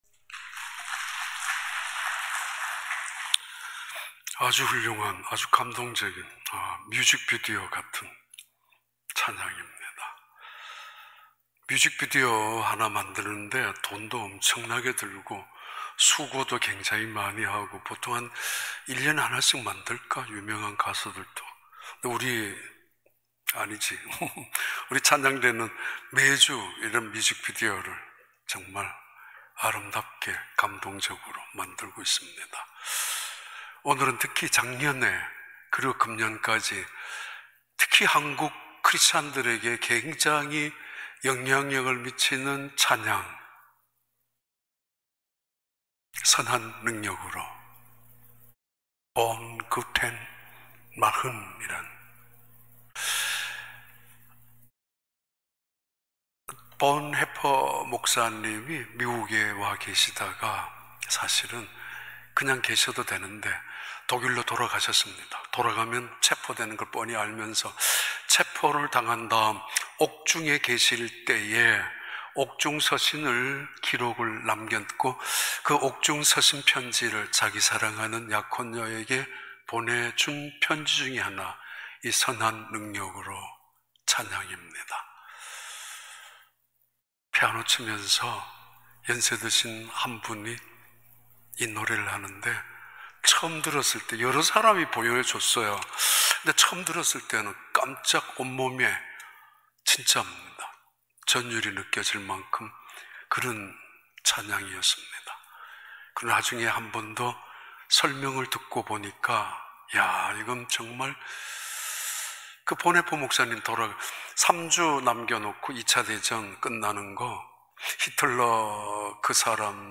2021년 2월 28일 주일 4부 예배